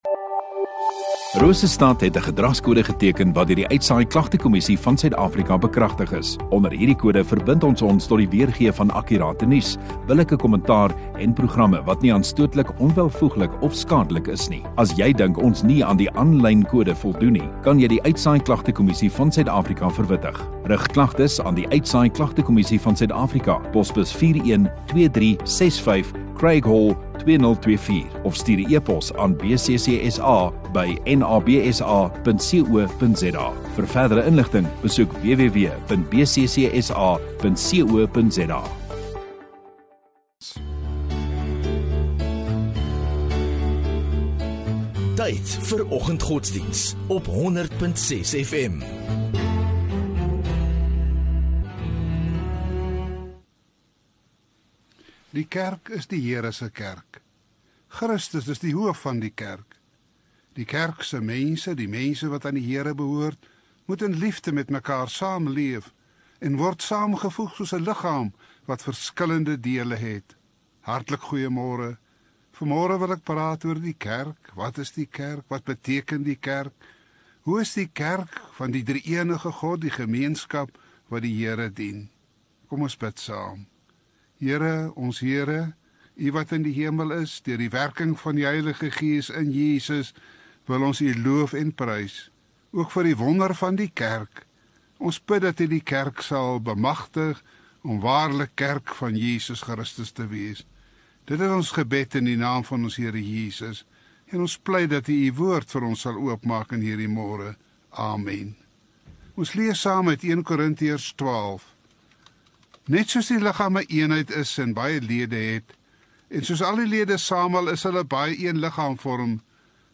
18 Sep Donderdag Oggenddiens